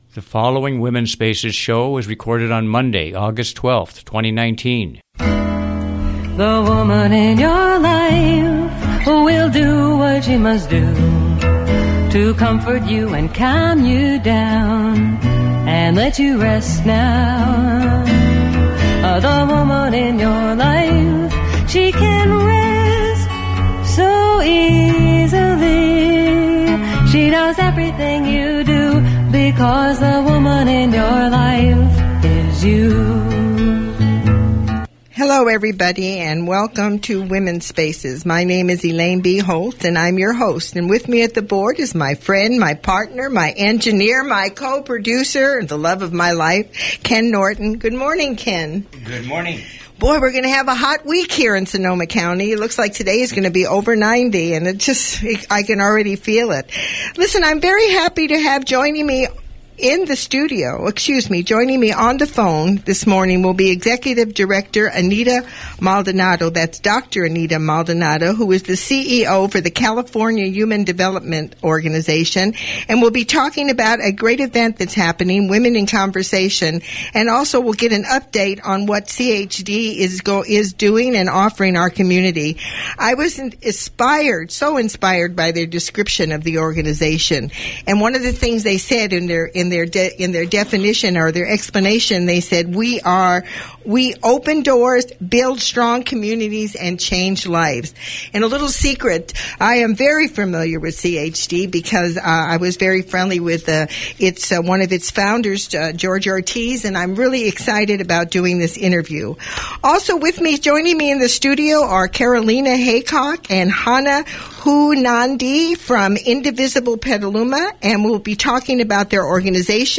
Original Radio Show ID: WSA190812Mp3 Player